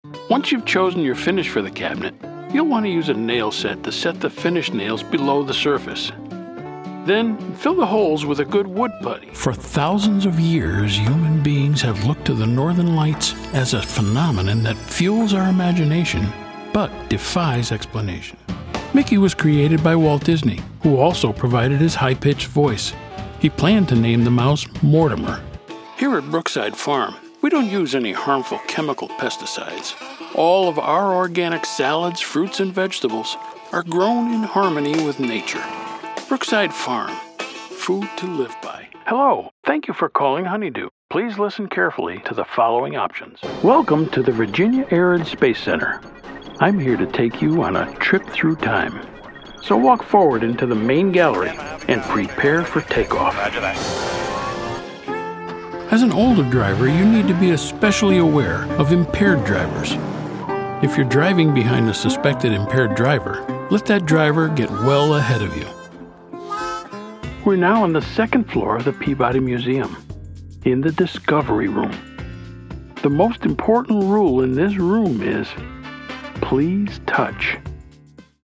WARM, GENUINE,INFORMATIVE, GUY NEXT DOOR
mid-atlantic
Sprechprobe: Industrie (Muttersprache):